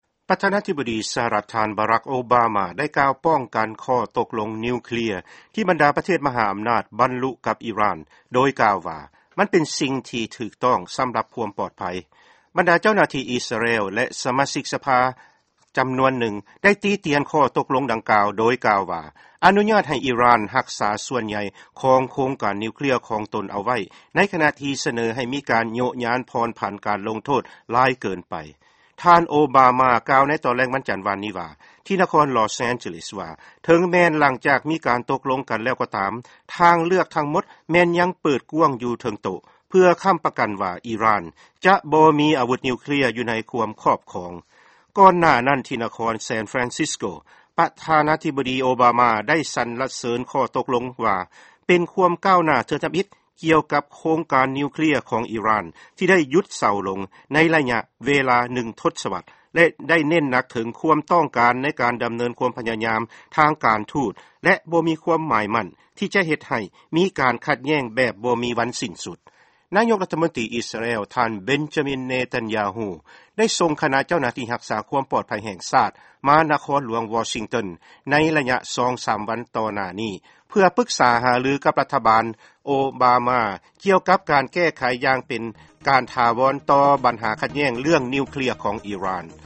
ຟັງຂ່າວ ກ່ຽວກັບ ອີຣ່ານ